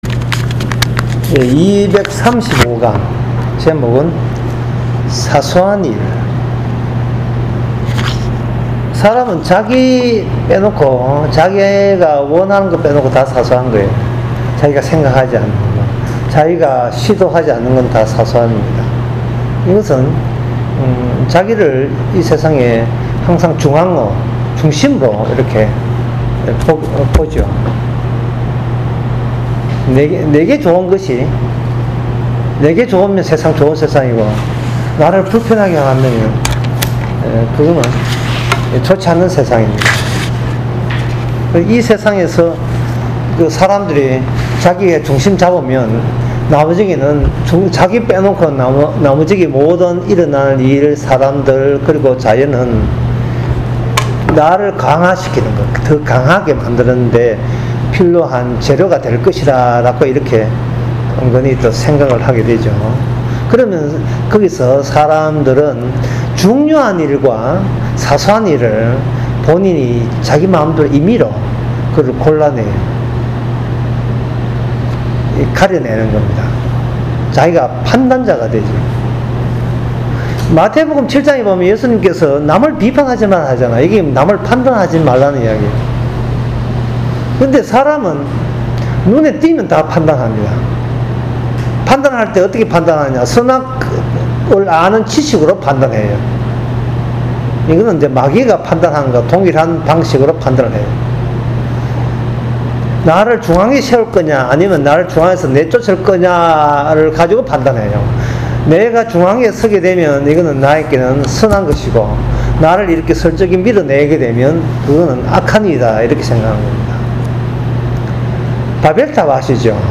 유튜브 강의